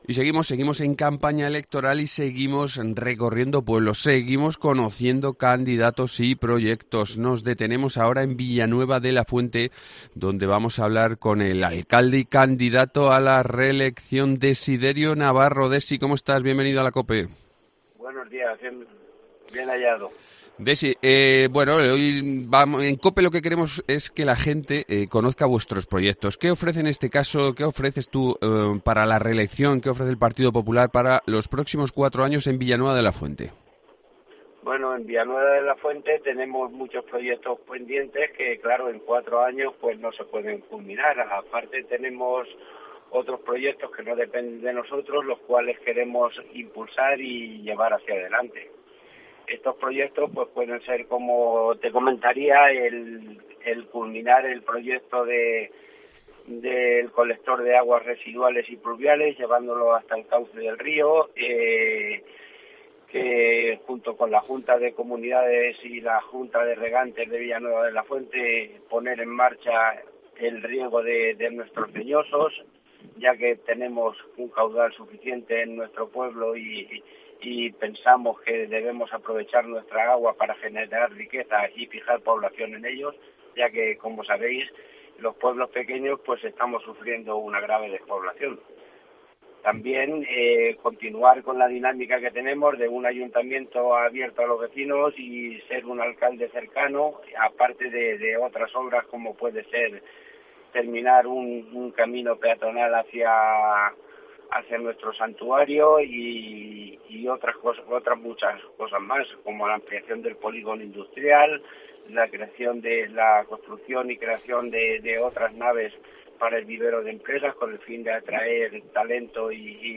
Entrevista
Alcalde de Villanueva de la Fuente y candidato a la reelección